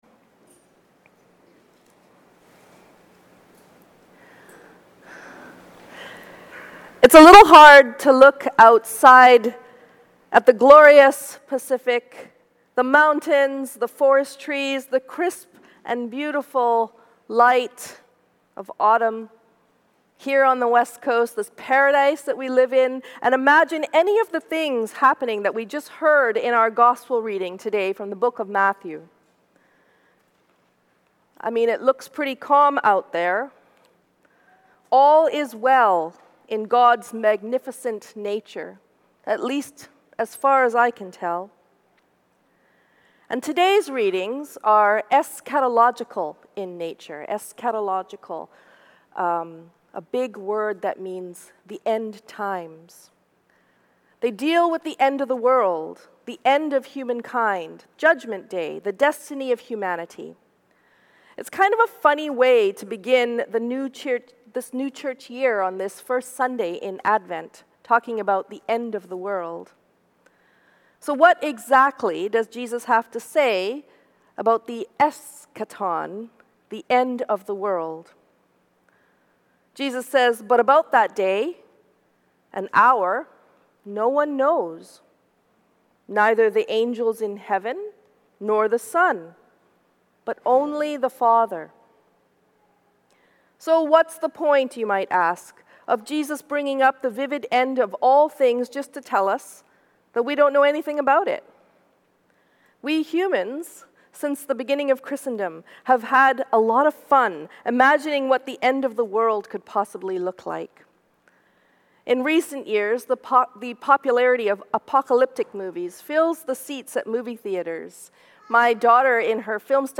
Sermon for Advent 1- Hope